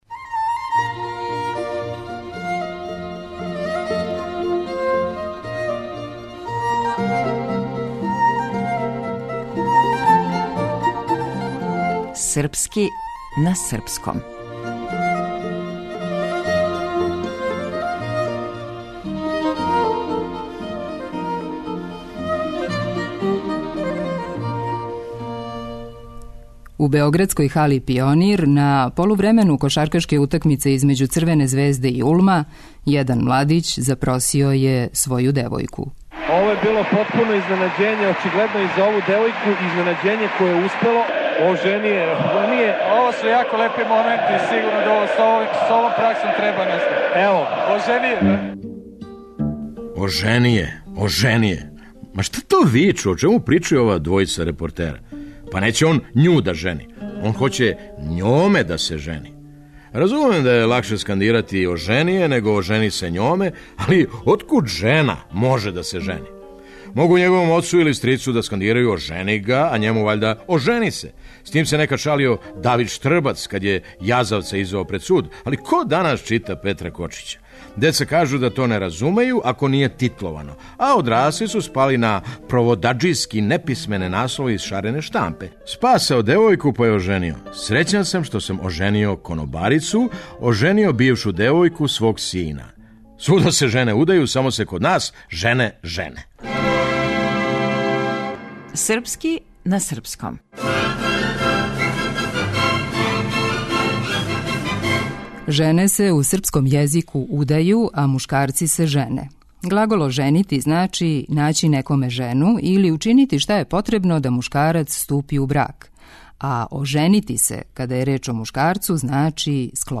Драмски уметници